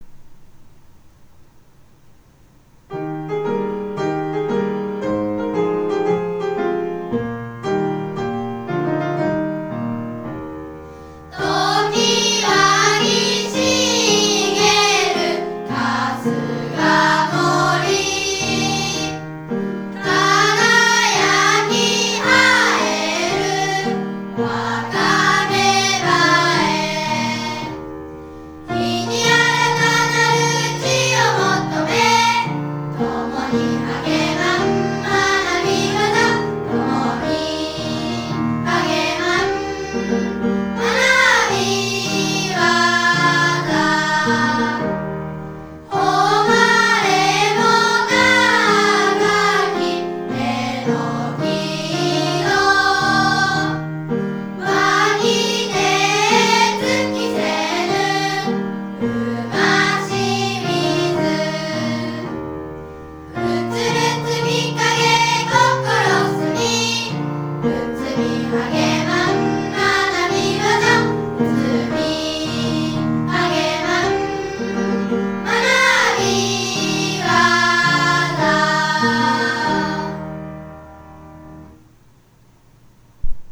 校歌 - 琴平町立榎井小学校